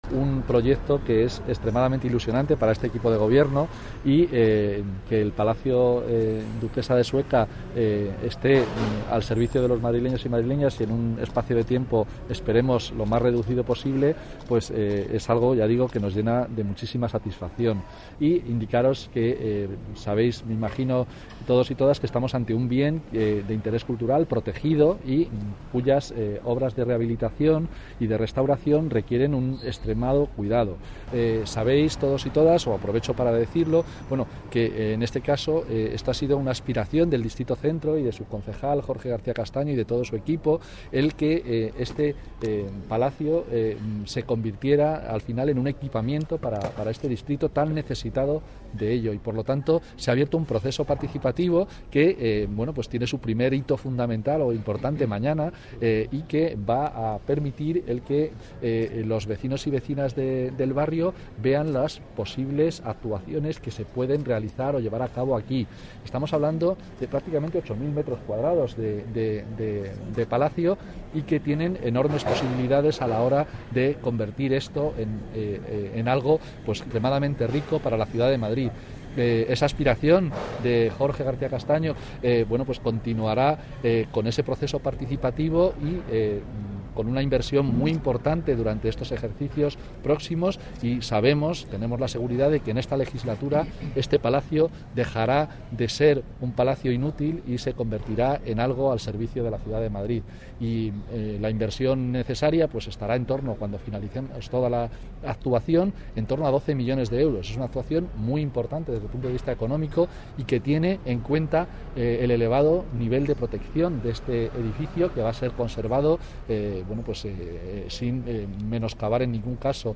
Nueva ventana:Declaraciones Carlos Sanchez Mato sobre el Palacio de Sueca